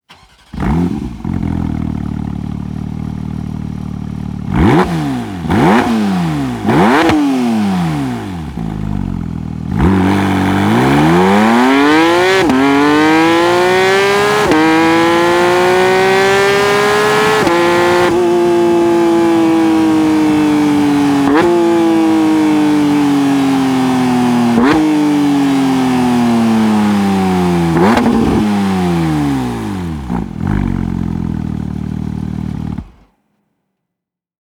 • Volume +4.1 dB
• Exhaust Audio — Slip-On Line
The Akrapovič Slip-On Race Line delivers a +4.1 dB increase, enhancing the natural tone of Porsche’s iconic flat-six engine. It produces a smooth, rich sound at cruising speeds and transitions to a sportier, more aggressive note at higher revs.